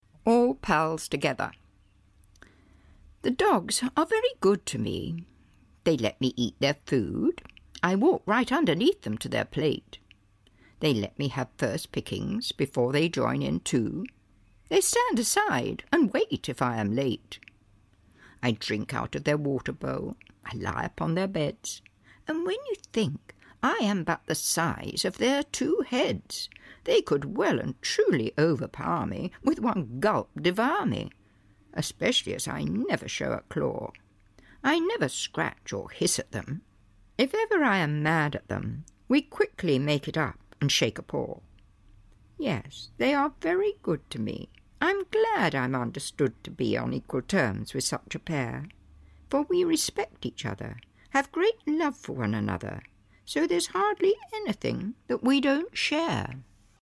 Chimney & Co. (EN) audiokniha
Ukázka z knihy